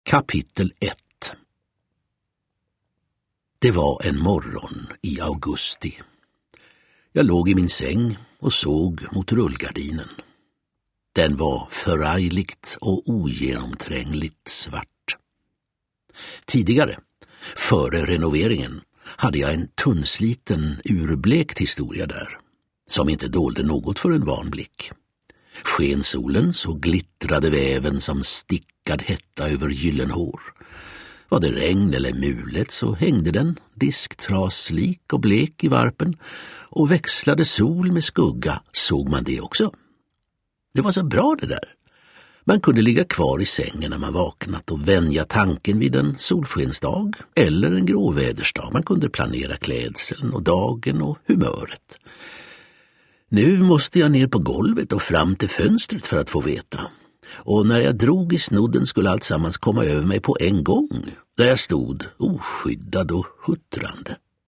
Berättare